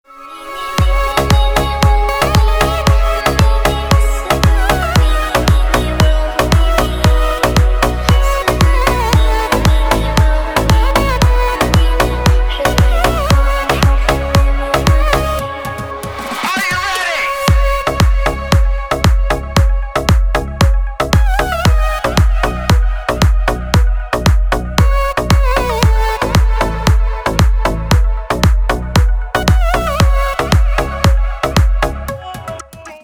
Нарезки зарубежных ремиксов
• Песня: Рингтон, нарезка